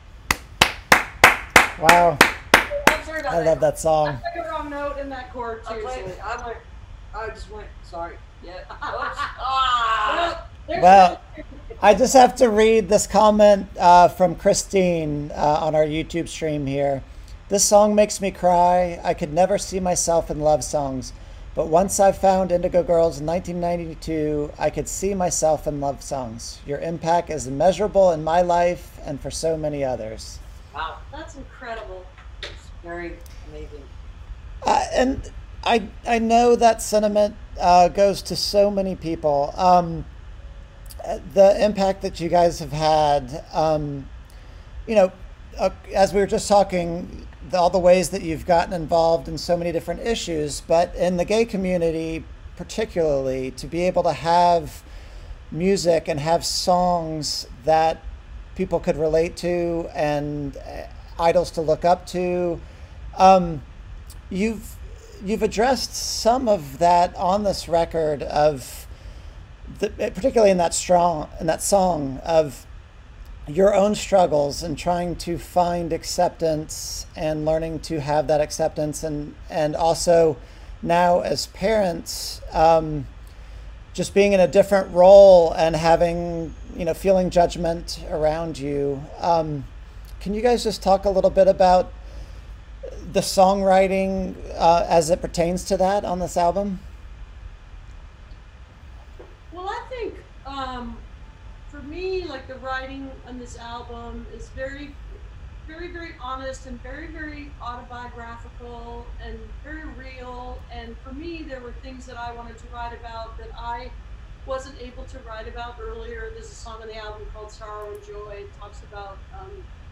(captured from the web broadcast, including audio issues at times)
07. interview (8:01)